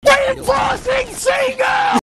Play, download and share Reinforcing Seagull original sound button!!!!
reinforcing-seagull.mp3